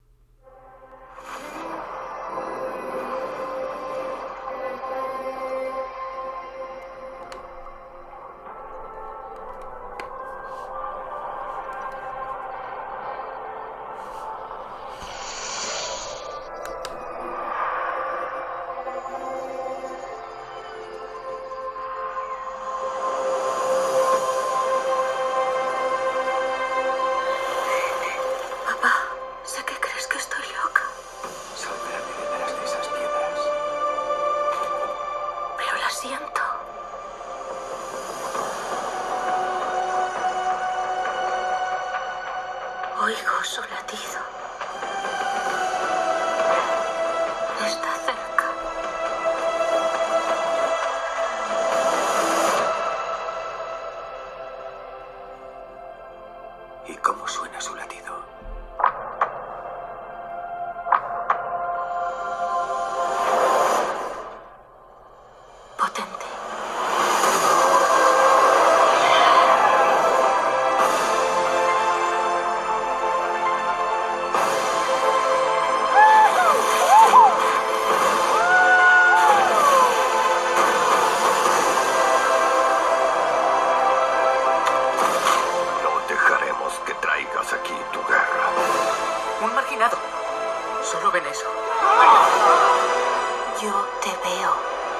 El MSI Raider GE67 HX cuenta con el mismo sistema de sonido que la serie GE67 consistente en dos altavoces de 2W denominados Duo Wave al darnos audio por ambas caras.
El resultado mejora al tener las salidas en los laterales, mostrando un sonido muy claro y detallado incluso a máximo volumen, que además es bastante elevado. Flojea un poco en los graves, dando mucho más protagonismo a agudos y medios, así al menos las voces y películas se escuchan muy bien.